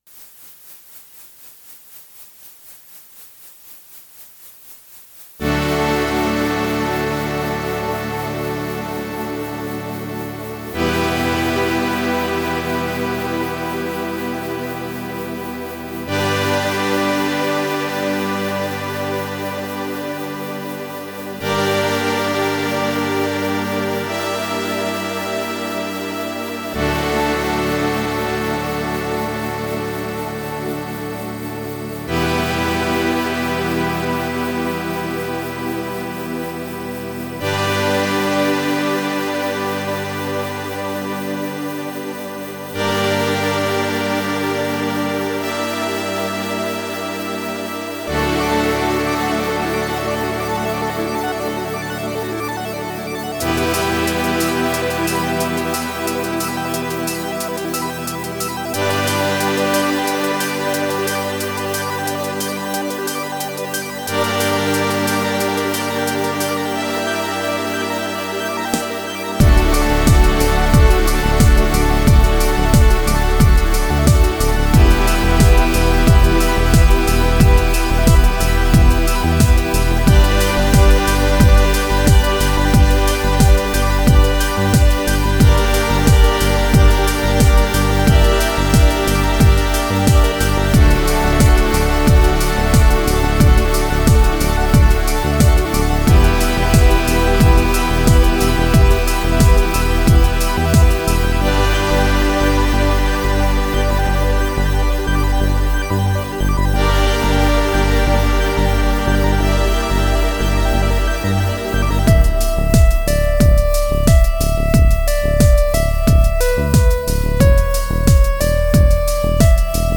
KORG Gadget cover